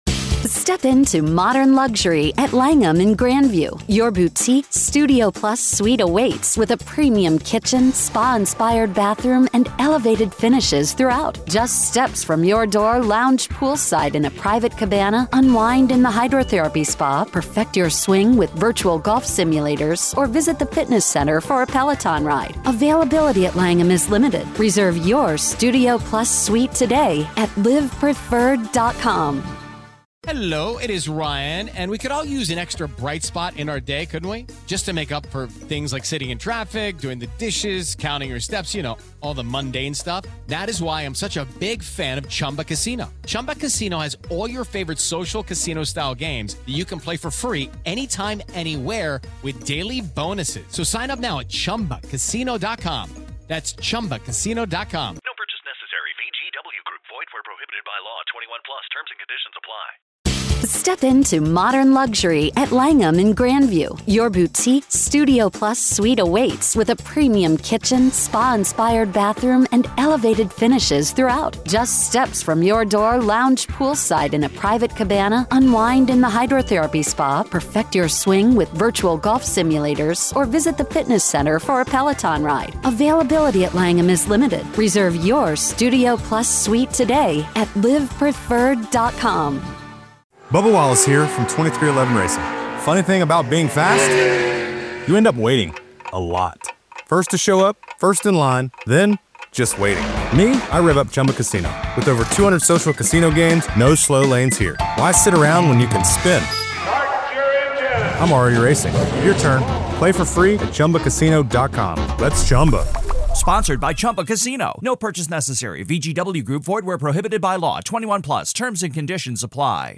The conversation underscores the ongoing confusion and uncertainty surrounding the trial, suggesting that a definitive truth may remain elusive even after the case is resolved.